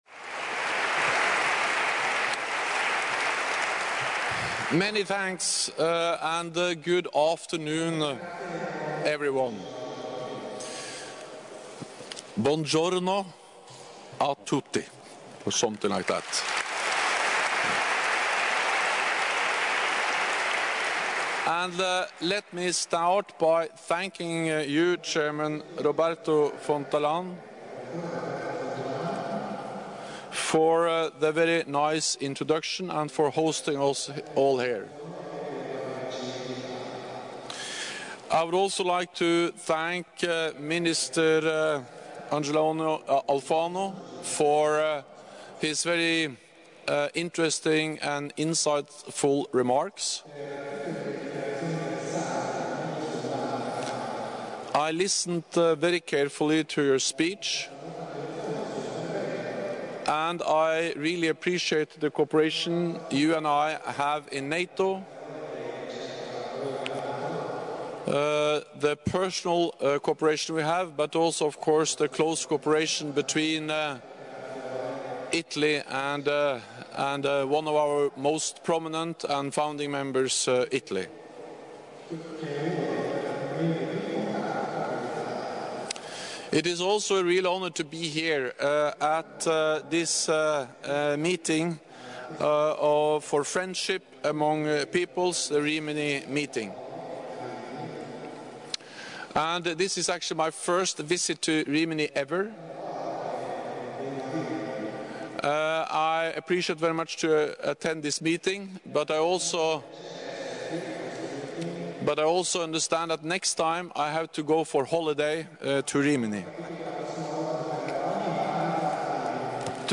ENGLISH - Q&A session following remarks by NATO Secretary General Jens Stoltenberg at the Rimini Meeting 24 Aug. 2017 | download mp3 ORIGINAL - Remarks by NATO Secretary General Jens Stoltenberg at the 38th Meeting for Friendship amongst Peoples in Rimini, Italy 24 Aug. 2017 | download mp3